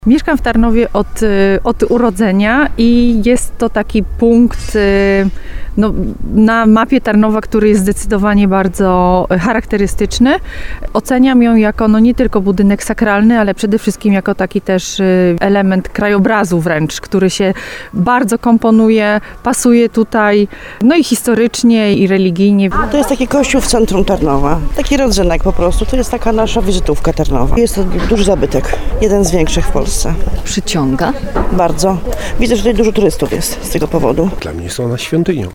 Oceniam ją jako, nie tylko budynek sakralny, ale element krajobrazu wręcz, który się bardzo komponuje, pasuje tutaj, historycznie i religijnie – mówi nam jedna z mieszkanek miasta.